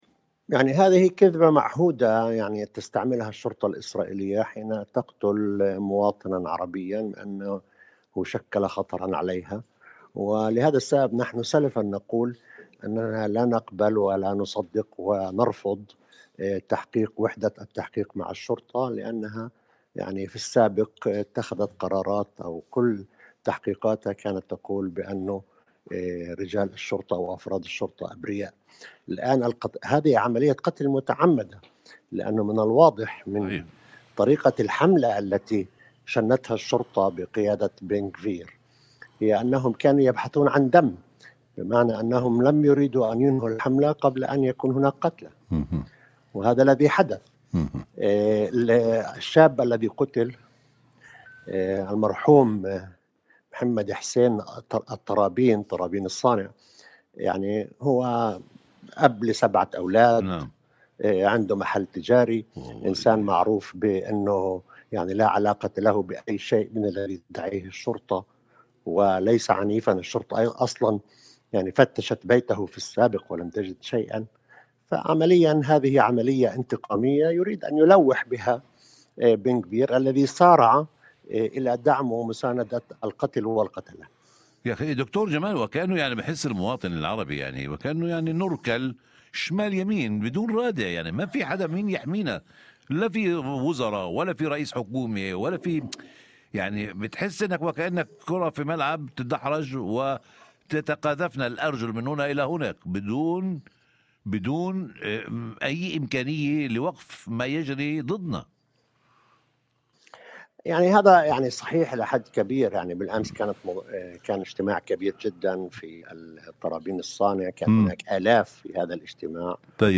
وجاءت تصريحات زحالقة خلال مداخلة على خلفية المهرجان والمسيرة الاحتجاجية التي انطلقت من ساحة مسجد ترابين، وجابت شوارع القرية وصولا إلى بيت الشعب، احتجاجا على الحصار المفروض على القرية، وذلك ضمن مهرجان التضامن مع أهالي ترابين الصانع، بدعوة من لجنة المتابعة العليا، ولجنة التوجيه العليا لعرب النقب، ومنتدى السلطات المحلية، والمجلس الإقليمي للقرى مسلوبة الاعتراف في النقب، وبمشاركة قيادات ورؤساء سلطات محلية عربية من النقب والمثلث والشمال.